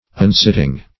Search Result for " unsitting" : The Collaborative International Dictionary of English v.0.48: Unsitting \Un*sit"ting\, a. Not sitting well; unbecoming.